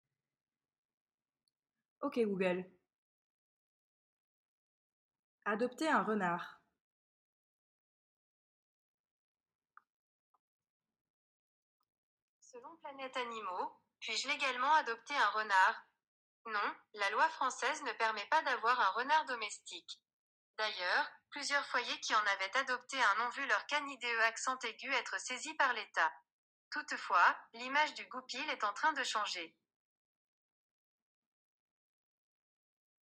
Fichier audio : résultat de recherche pour la requête vocale « adopter un renard »
position-zero-voice-search.mp3